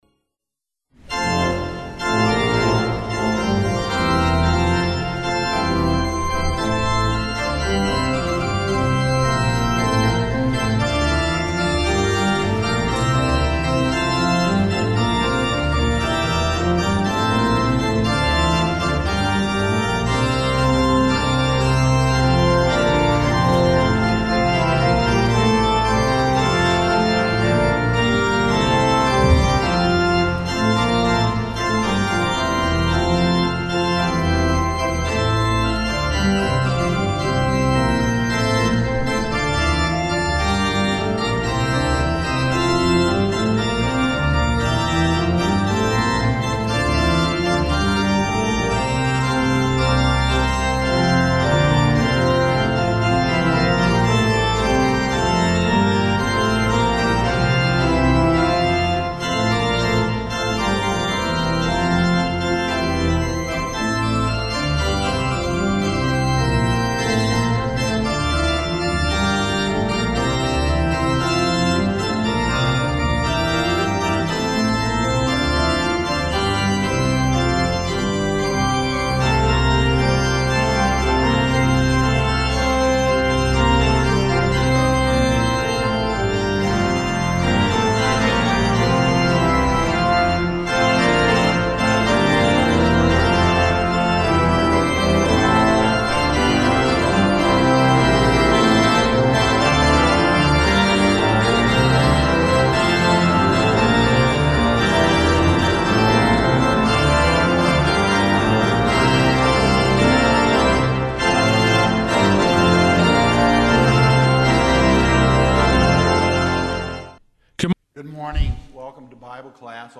Hear the Bible Study from St. Paul's Lutheran Church in Des Peres, MO, from December 8, 2024.